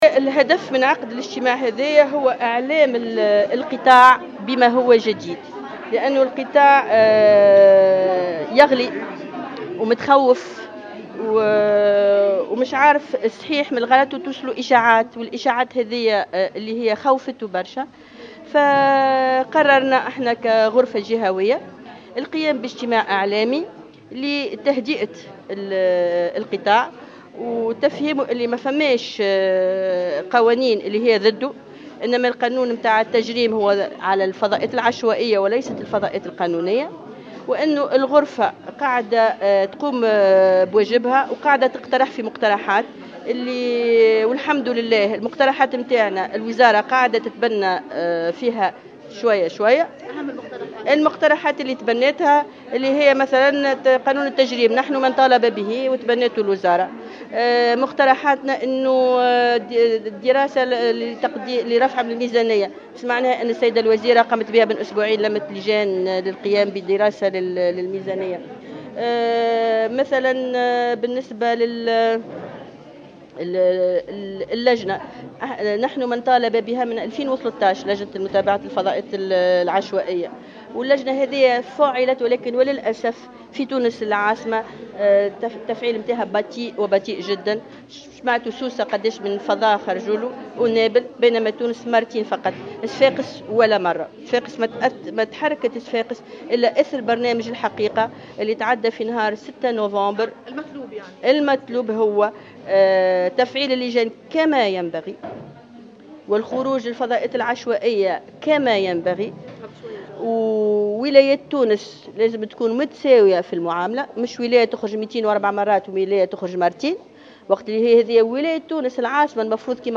تصريح إعلامي